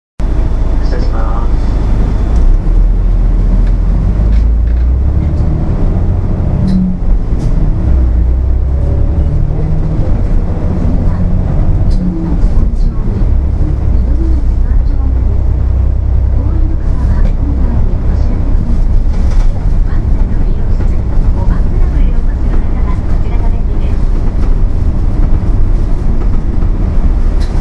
車内放送(最新)     ドアが閉まった際に「発車致します、ご注意下さい」と流れます。